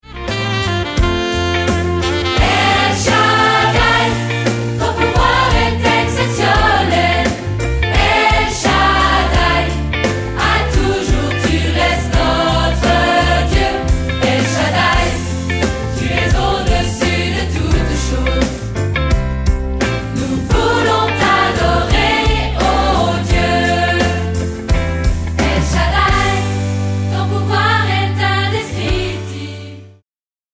• Chants de louange pour églises, familles et autres
• Chorale et solistes